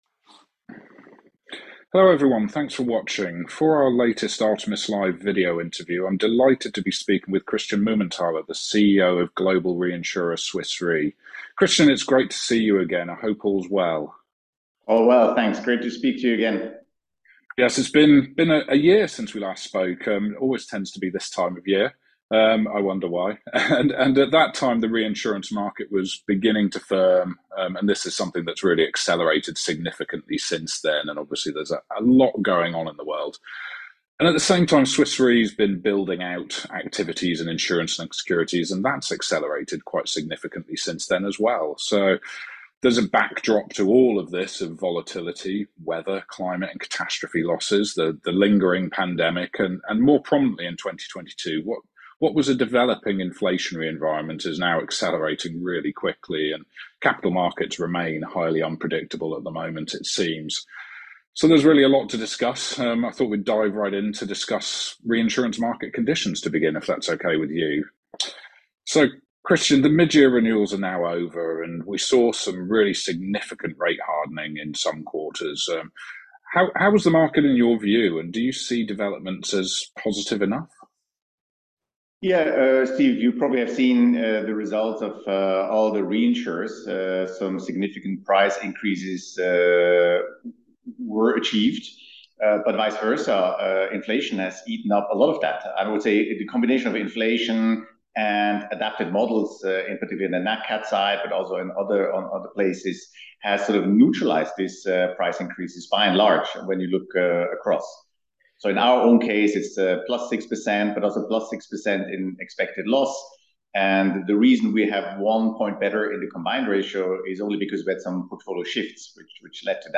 Christian Mumenthaler, CEO, Swiss Re interview - Sep 2022 - Reinsurance hardening to continue